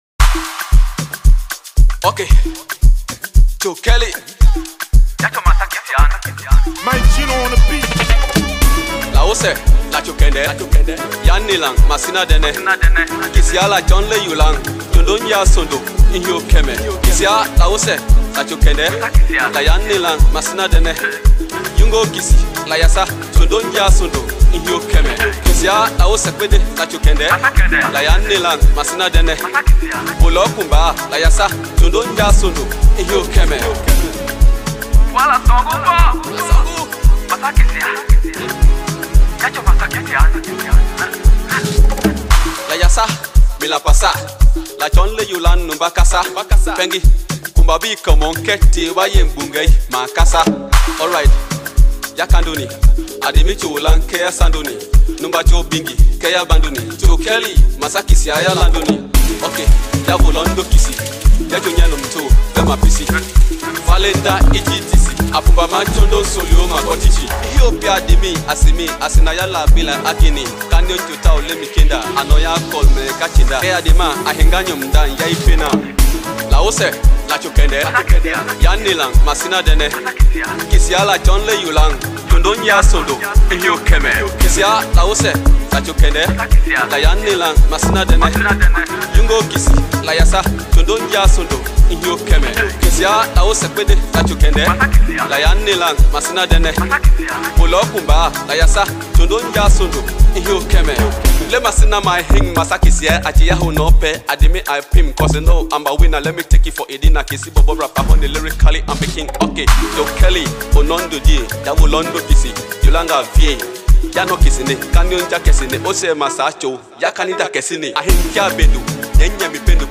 indigenous song